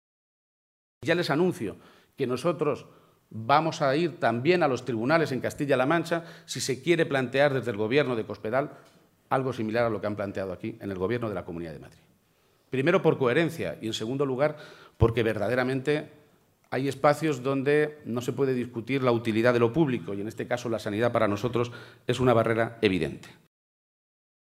García-Page se pronunciaba de esta manera durante el ciclo de conferencias del Foro Nueva Economía, en Madrid, y en el que ha sido presentado por la Presidenta de Andalucía, Susana Díaz, que ha dicho del líder socialista castellano-manchego que es “un buen político, un buen socialista y un buen alcalde”.